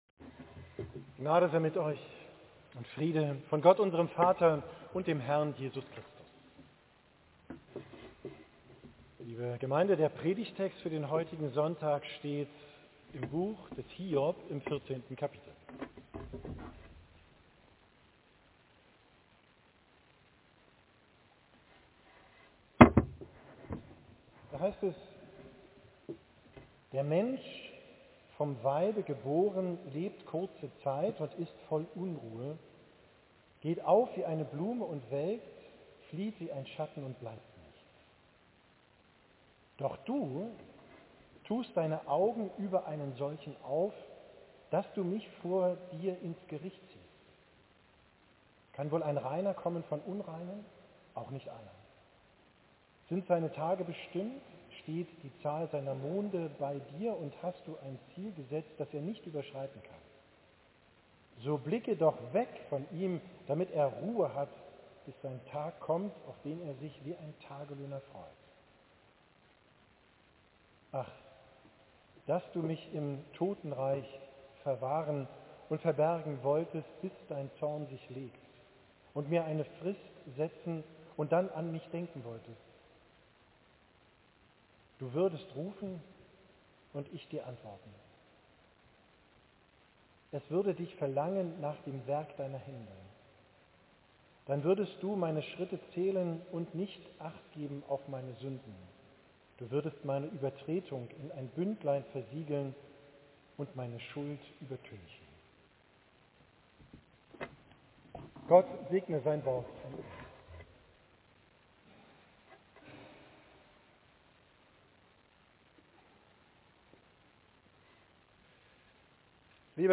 Predigt vom vorletzten Sonntag im Kirchenjahr, 16.